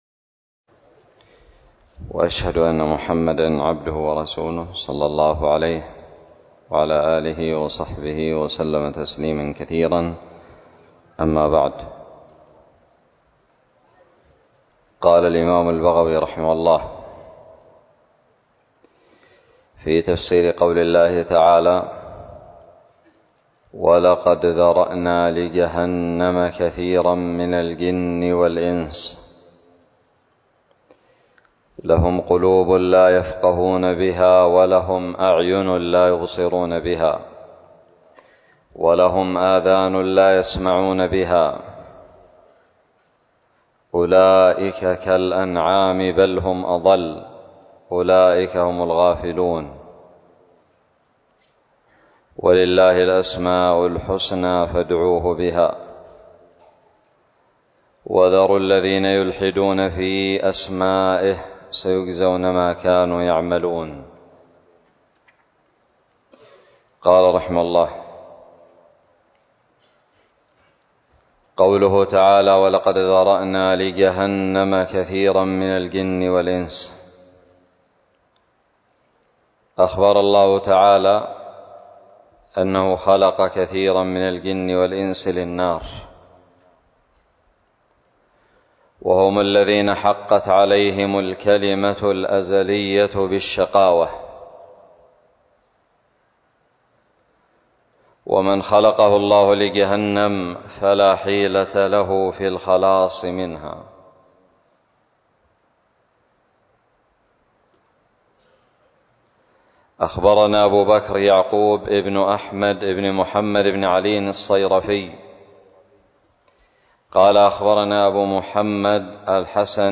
الدرس الثاني والأربعون من تفسير سورة الأعراف من تفسير البغوي
ألقيت بدار الحديث السلفية للعلوم الشرعية بالضالع